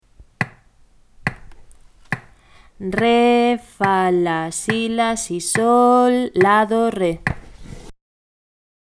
Cando aparece esta equivalencia, o pulso sempre é o mesmo.
Pero ao executalo pareceravos que hai un cambio de tempo ou velocidade, pero non é así.
Esa sensación débese a que no compás de 3/4 nun pulso entran 2 corcheas, mentres que no 6/8 entran 3, así que pareceravos que ides moito máis rápido, pero mirade o metrónomo e veredes que segue igual.